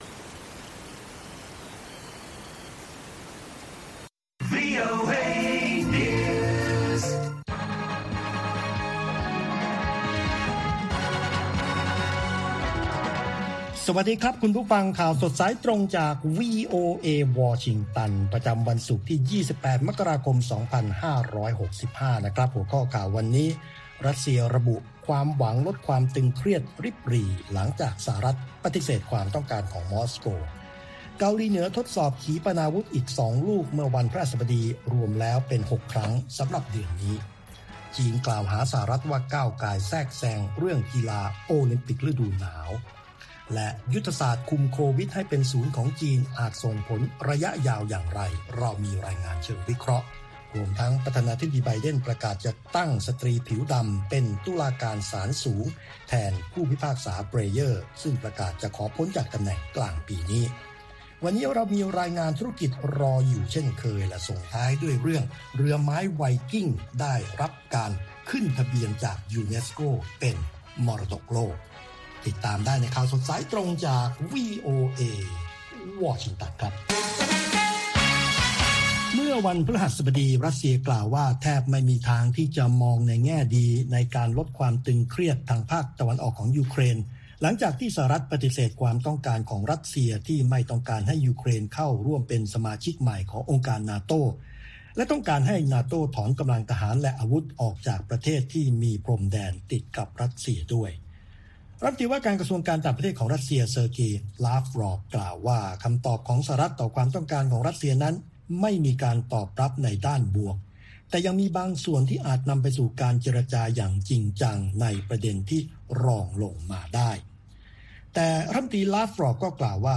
ข่าวสดสายตรงจากวีโอเอ ภาคภาษาไทย ประจำวันศุกร์ที่ 28 มกราคม 2565 ตามเวลาประเทศไทย